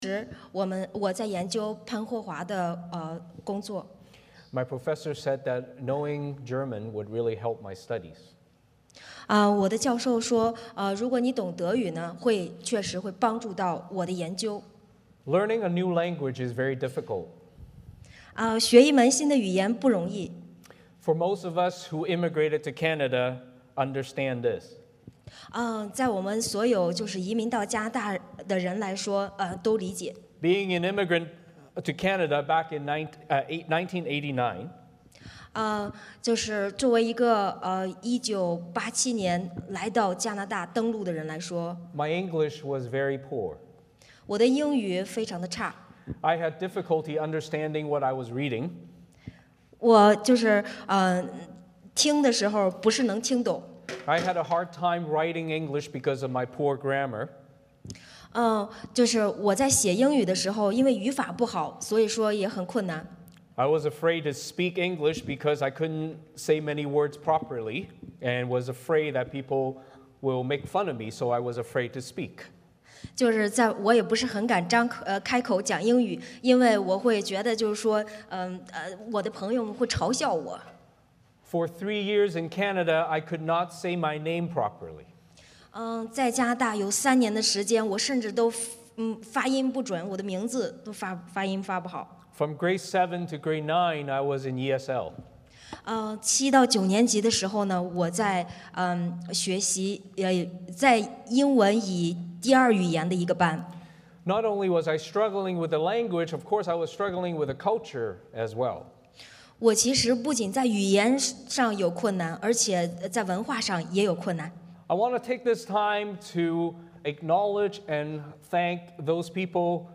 欢迎大家加入我们国语主日崇拜。
诗篇 46篇 Service Type: 主日崇拜 欢迎大家加入我们国语主日崇拜。